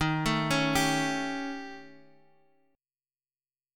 D#7b5 chord